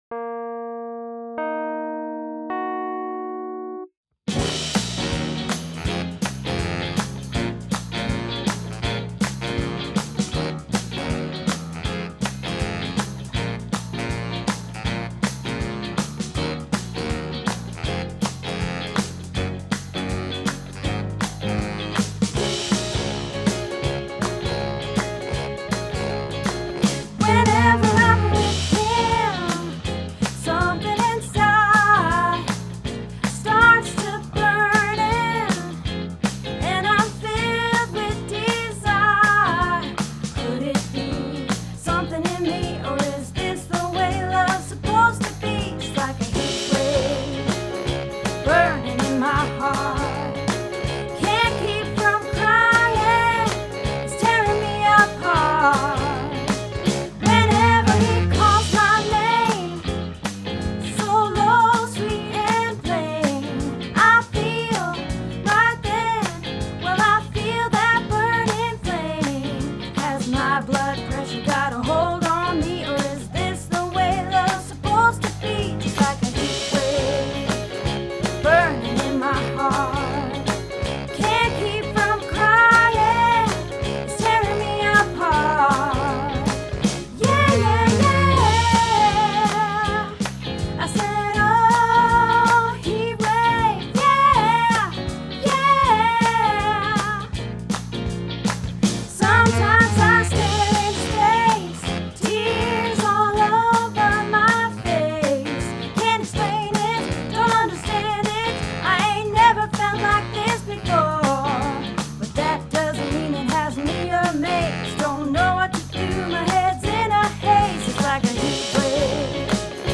Heatwave - Alto